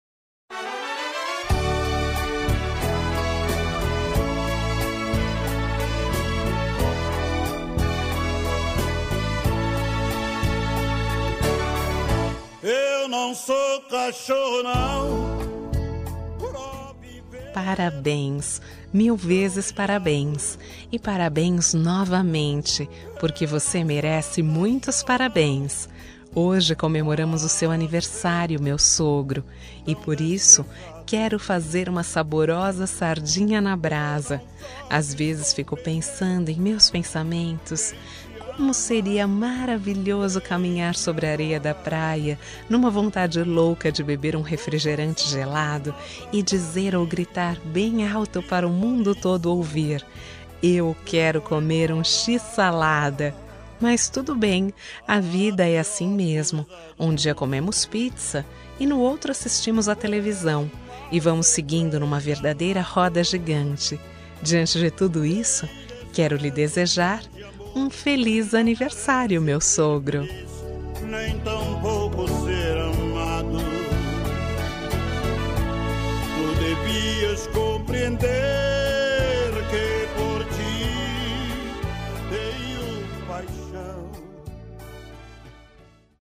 Aniversário de Humor – Voz Feminina – Cód: 200117